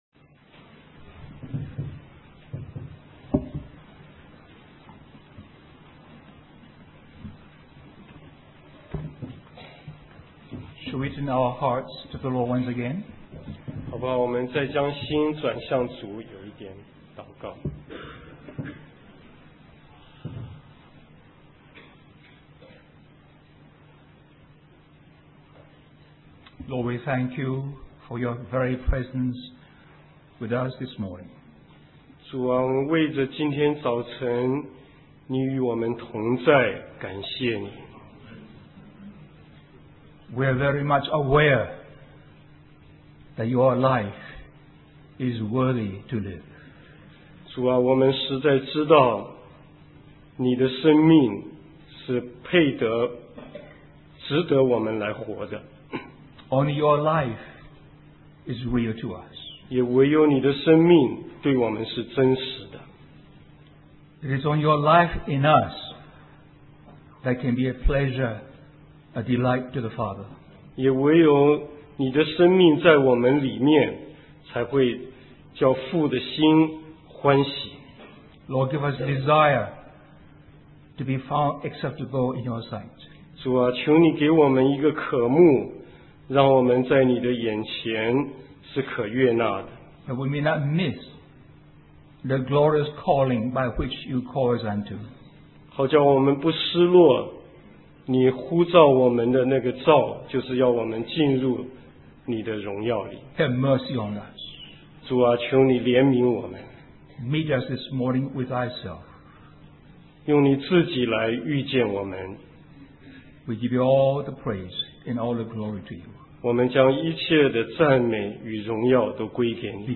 In this sermon, the speaker shares a vivid dream that a brother had about believers standing before the judgment seat of Christ.